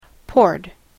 発音
• / pɔrd(米国英語)
• / pɔ:rd(英国英語)